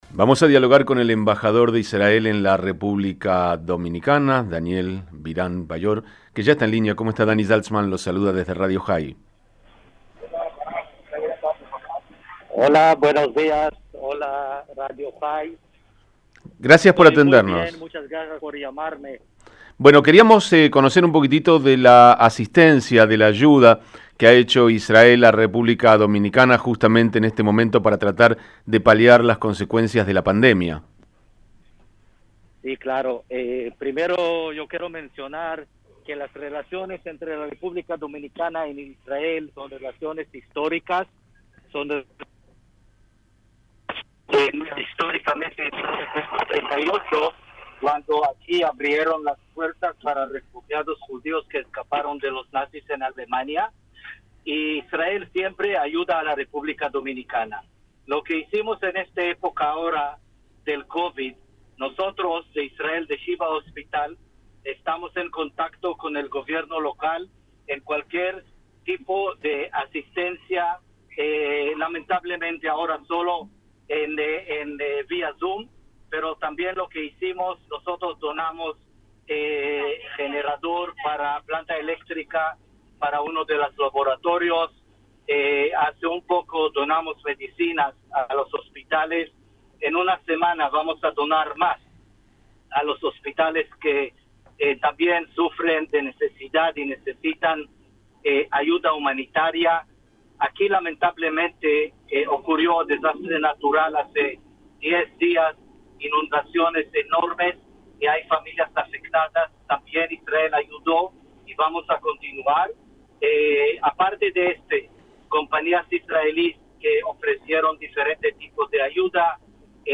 Radio Jai dialogó con el embajador de Israel en la República Dominicana Daniel Biran, para conocer sobre la ayuda que Israel presta a ese país para paliar las consecuencias de la pandemia.